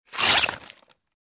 Звуки, когда меч вонзают или разрубают тела
Кровавый звук